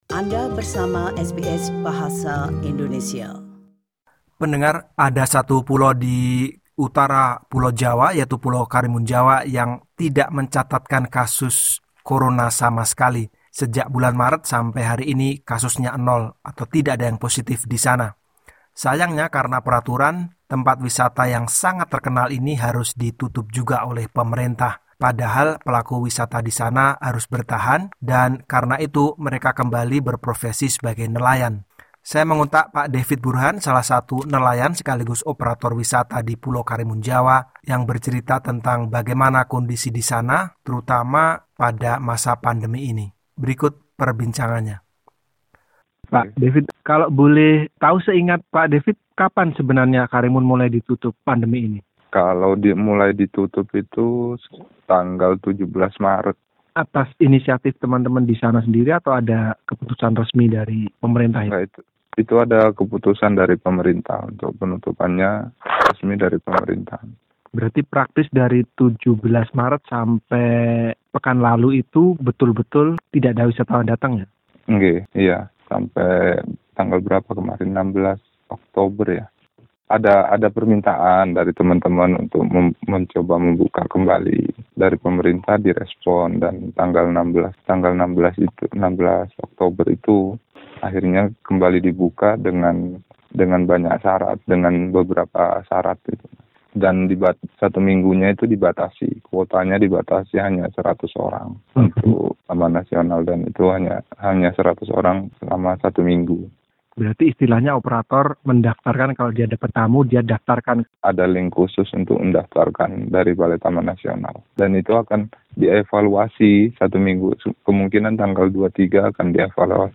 salah satu pegiat wisata di Karimunjawa berbincang mengenai daerah wisata itu.